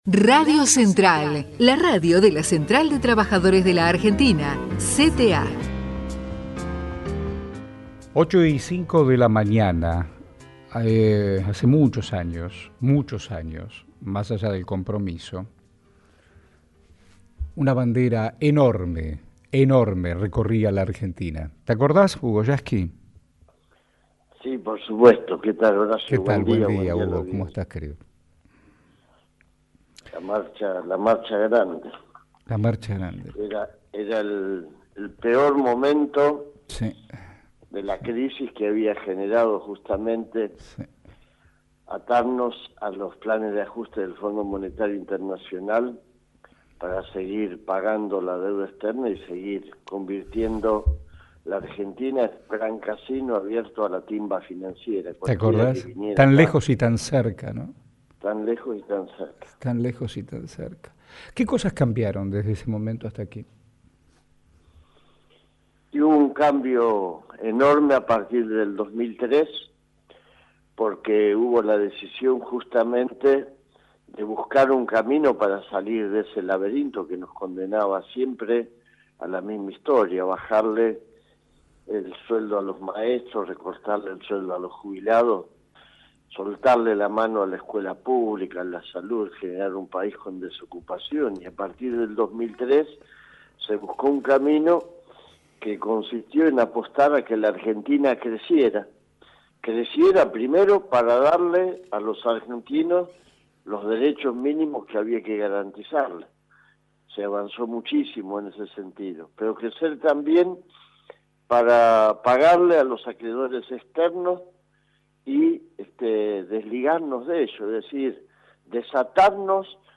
El secretario General de la CTA entrevistado en la Radio Pública